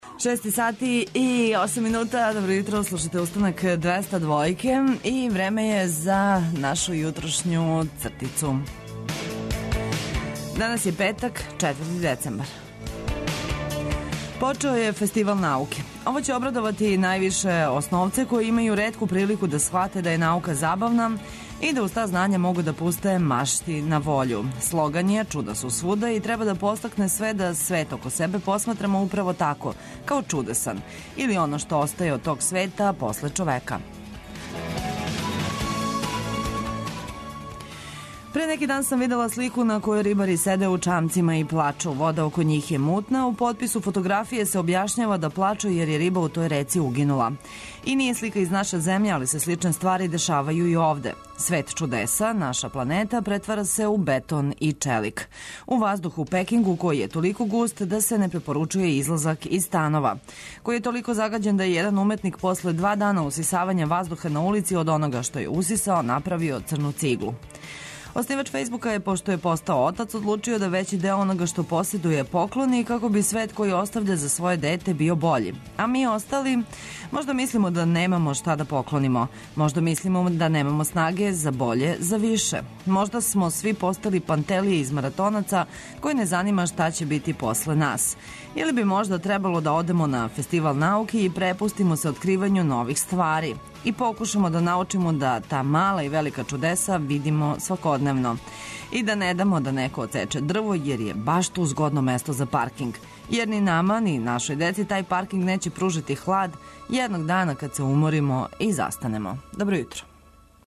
А да би буђење и овог петка било лагано, спремили смо вам добре ритмове, неопходне информације и мрвицу хумора.